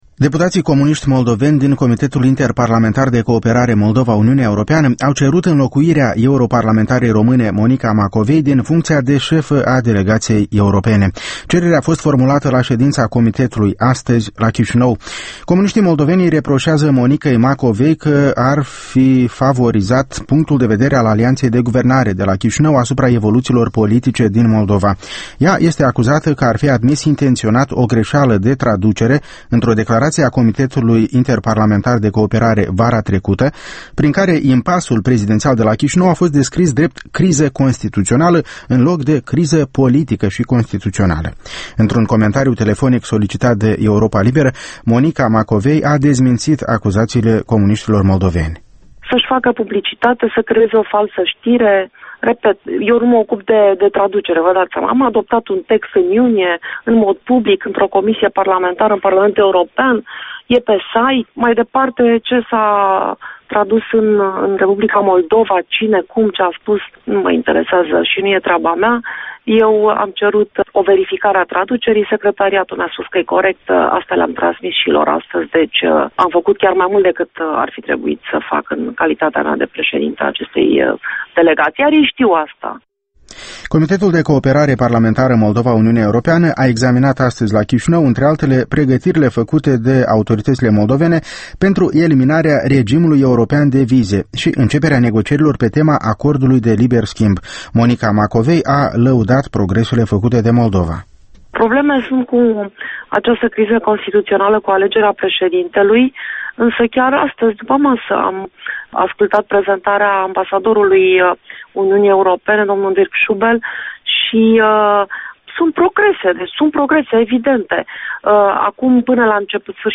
Interviu cu Monica Macovei